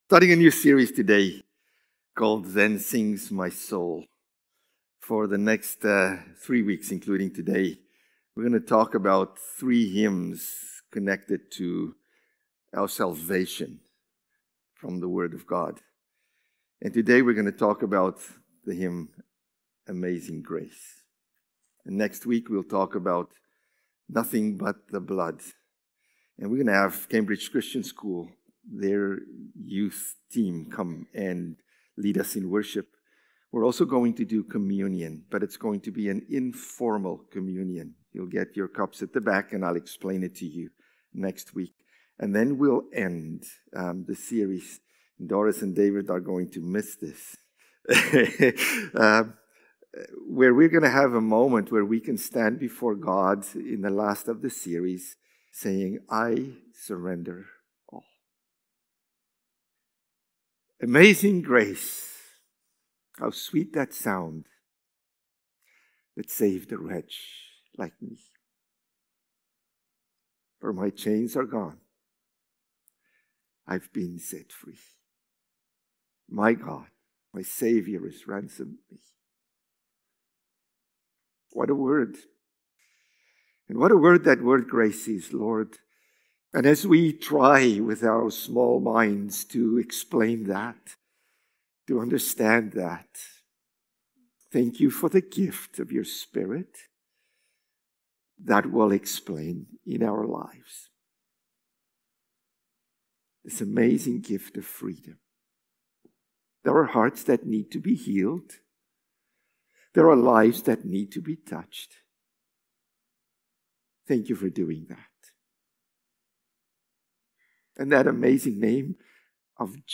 November-10-Sermon.mp3